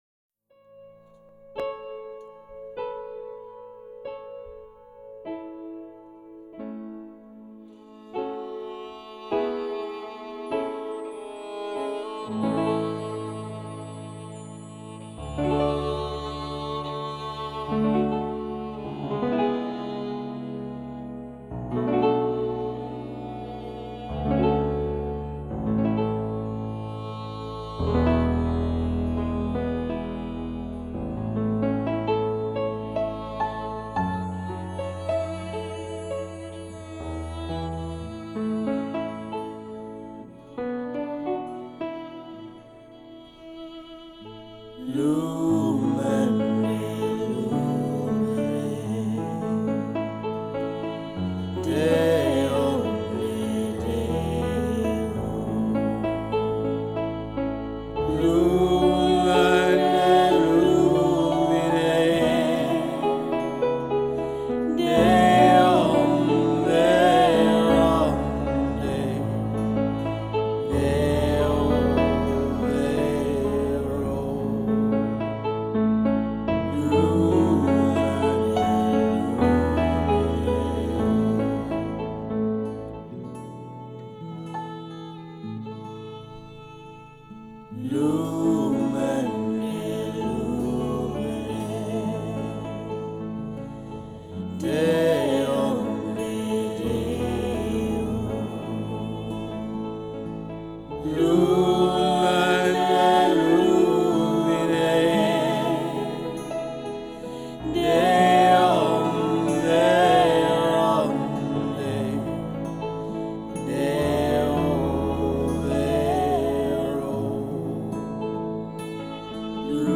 01-lumen-de-lumine-piano-violin.m4a